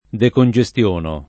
decongestionare
vai all'elenco alfabetico delle voci ingrandisci il carattere 100% rimpicciolisci il carattere stampa invia tramite posta elettronica codividi su Facebook decongestionare v.; decongestiono [ dekon J e S t L1 no ]